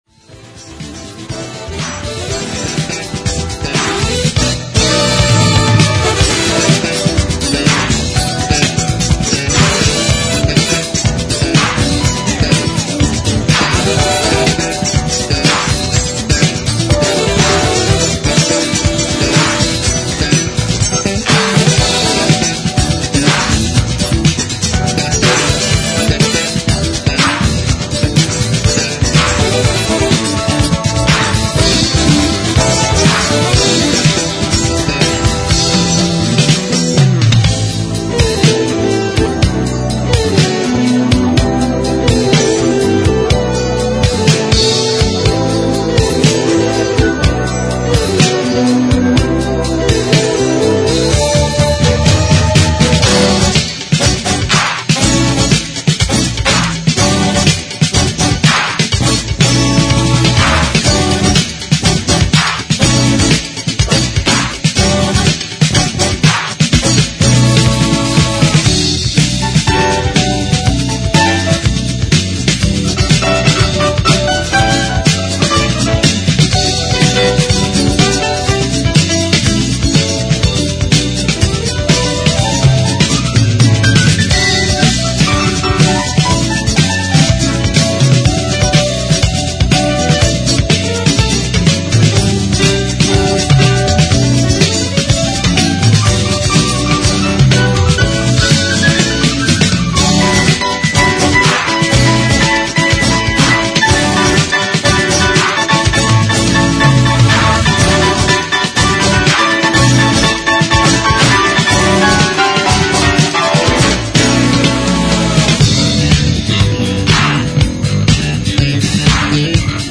〜instrumental〜